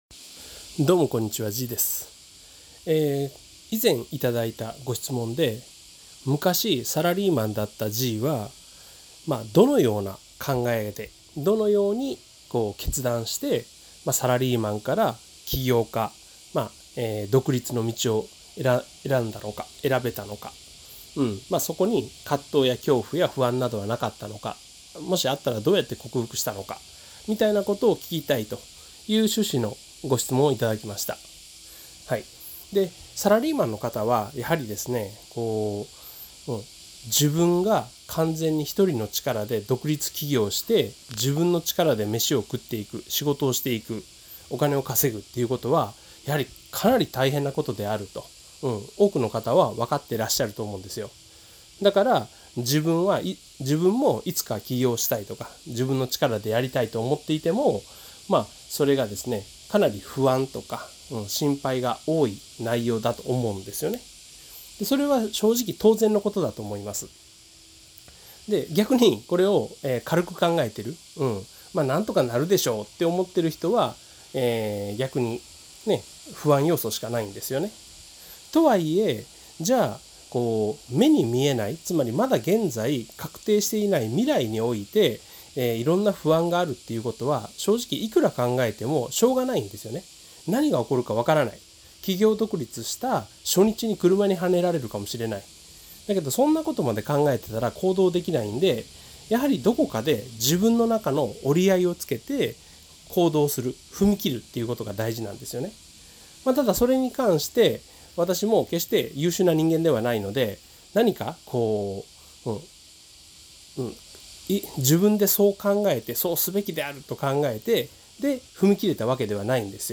このTHEORYは、色んな分野で結果を出している人にインタビューをし その人の成功の原因になるTHEORYを聞き出そうというコンセプトです。